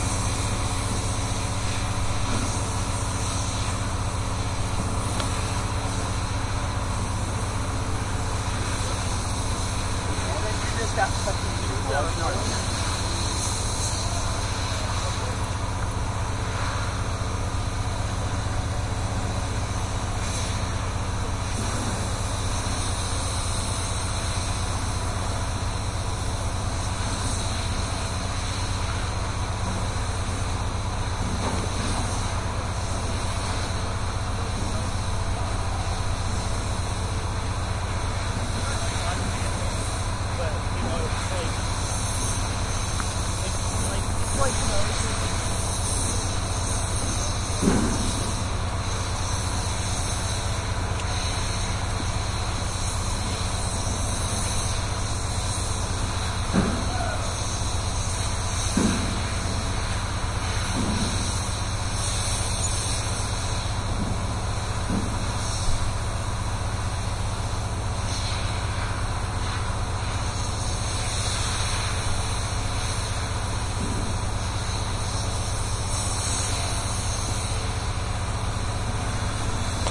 建筑2
描述：很多很好的施工现场的声音。哔哔声备份，大型机械运行和移动负载，锤子和发动机。
标签： 机械 机械 建筑 机器 工业
声道立体声